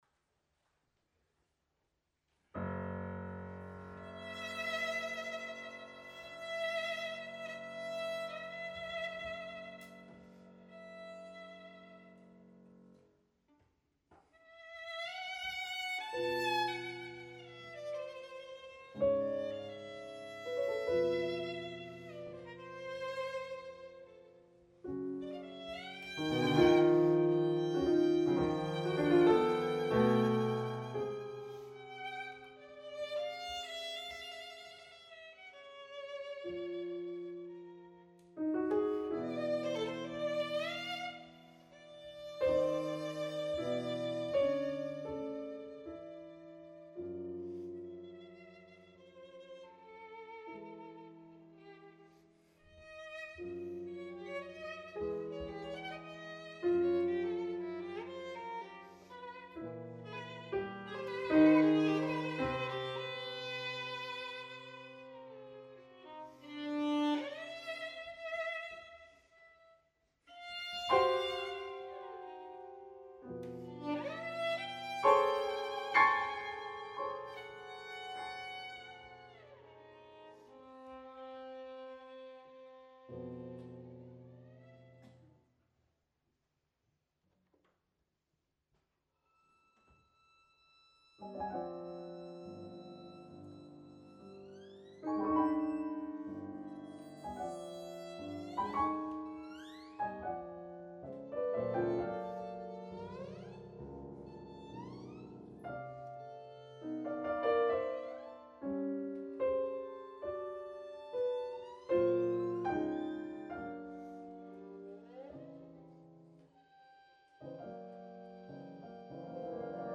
Bartók in turn builds his Sonata around a folk-derived motto played by the violin in almost the first bars.
Venue: Bantry Library
Instrumentation: vn, pf Instrumentation Category:Duo
violin
piano